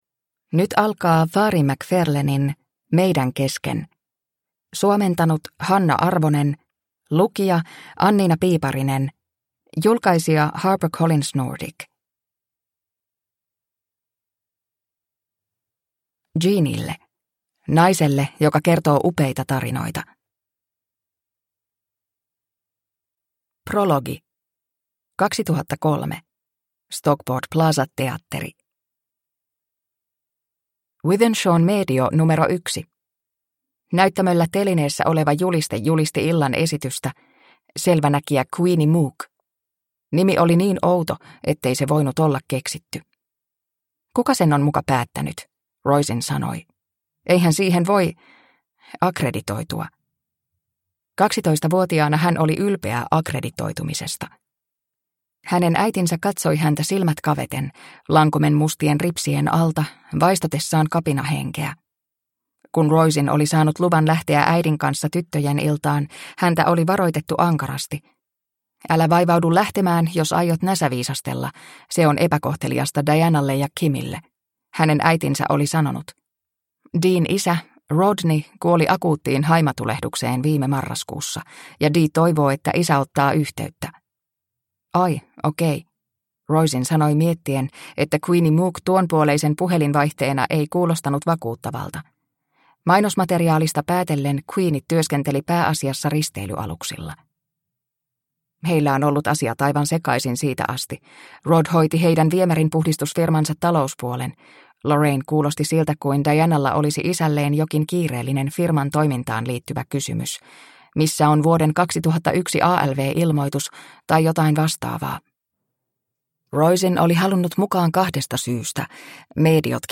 Meidän kesken – Ljudbok – Laddas ner